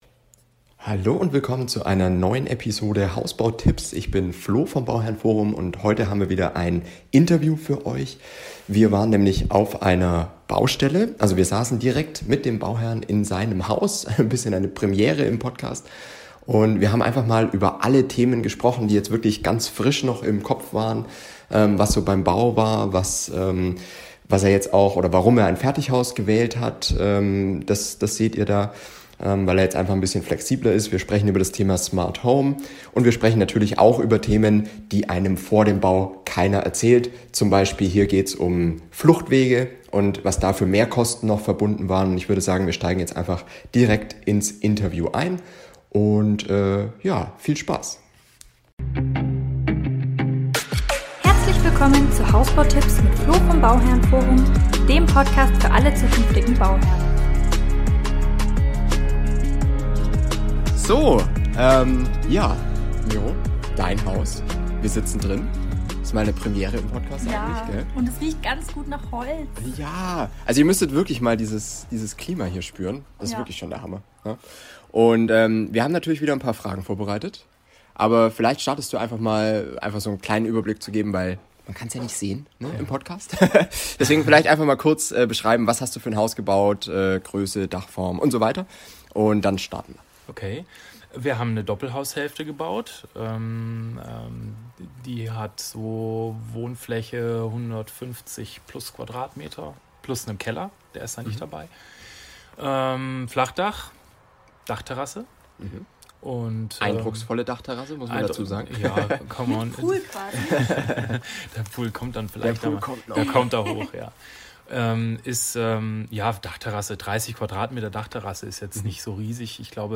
Wir haben Ihn auf der Baustelle besucht und über viele Themen gesprochen. Was er in Musterhäusern erlebt hat, was bei Ihm teurer wurde als gedacht und was er Zum Thema Smart Home sagt, erfahrt ihr in dieser Episode.